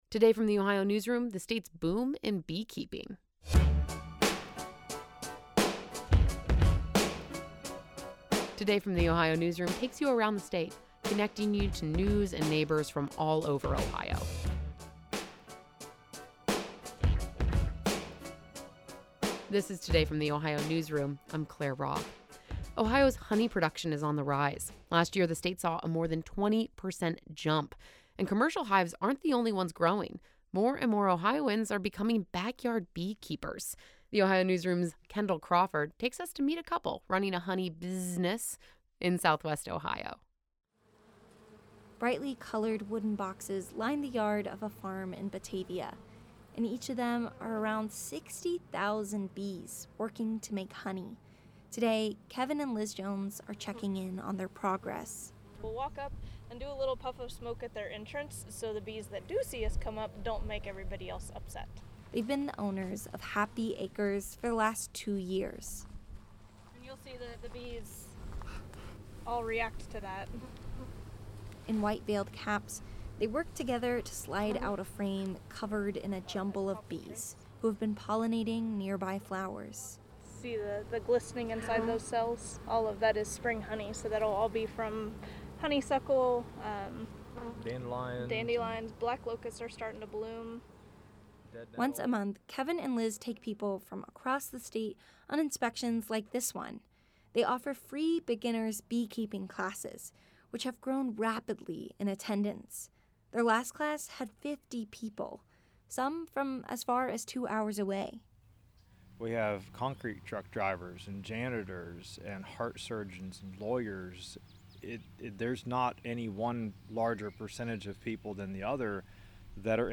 The colony has been pollinating nearby flowers.
bees-final-web.mp3